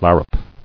[lar·rup]